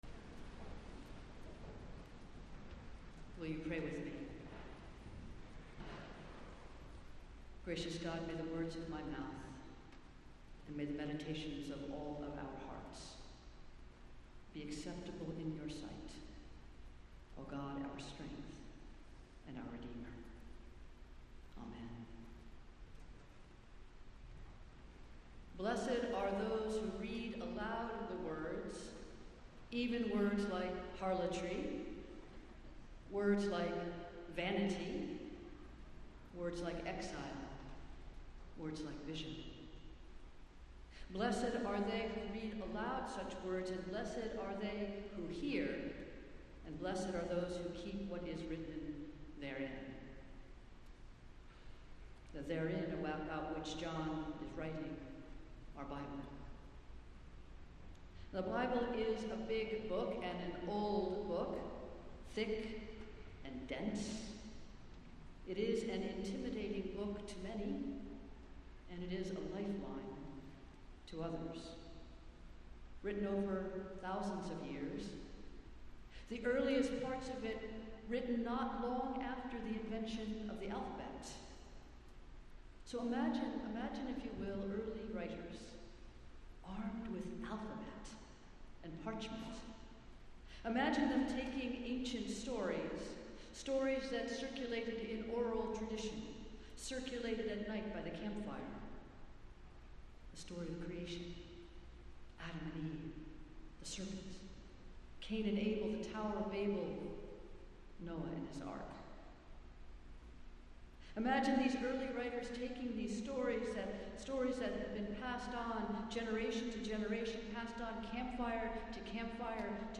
Festival Worship - Twelfth Sunday after Pentecost